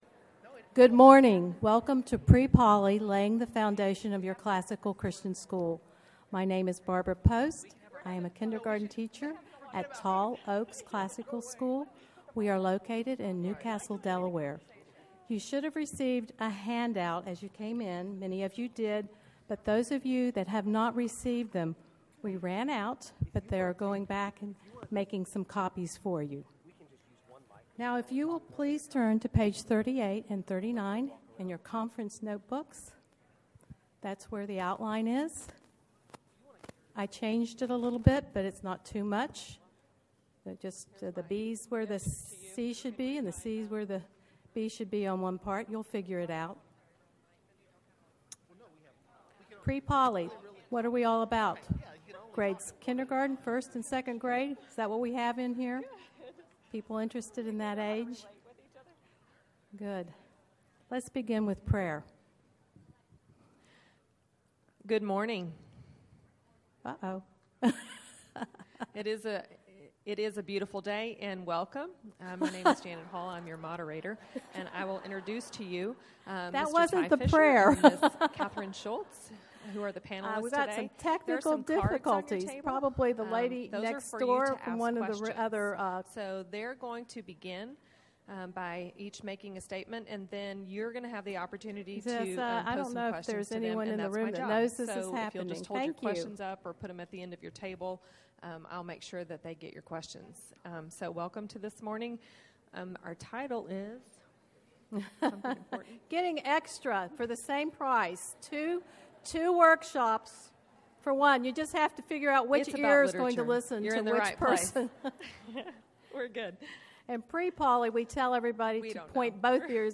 2012 Workshop Talk | 0:58:20 | K-6, Literature